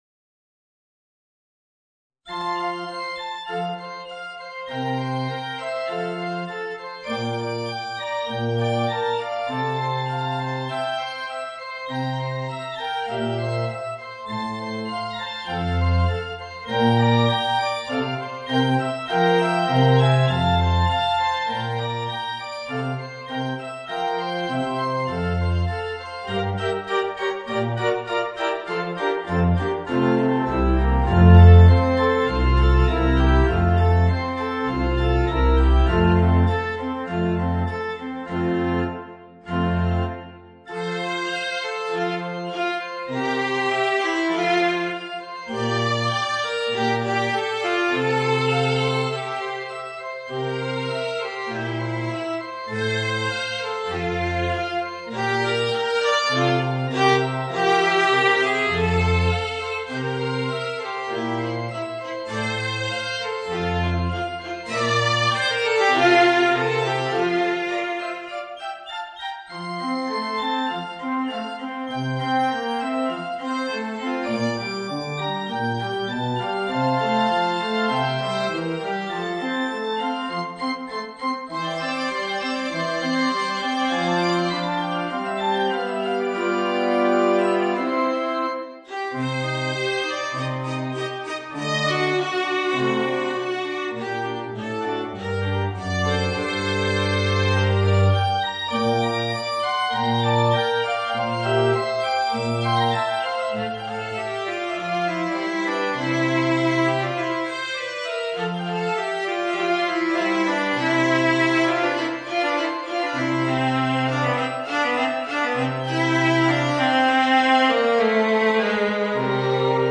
Viola and Organ